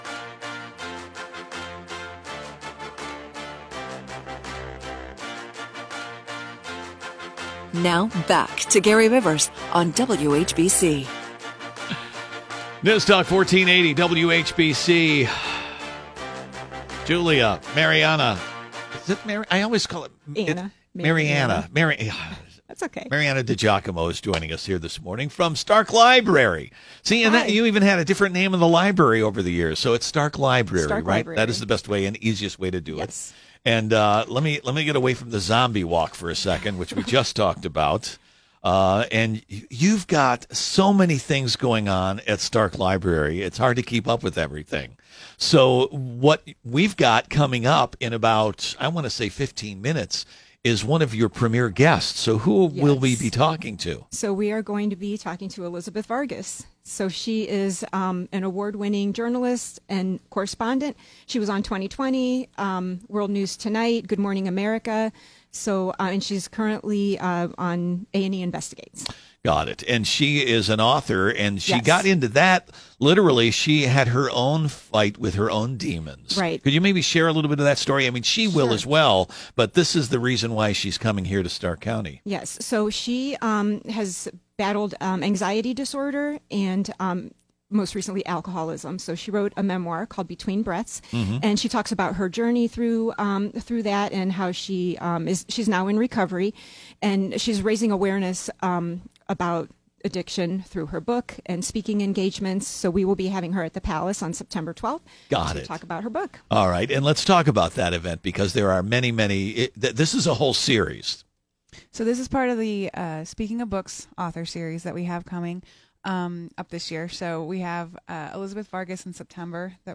An Interview with Author & Journalist Elizabeth Vargas